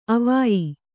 ♪ avāyi